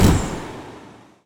Human_03_Hit.wav